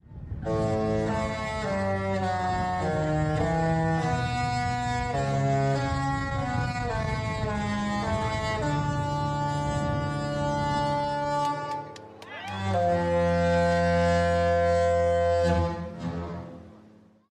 Disney Cruise Ship Horm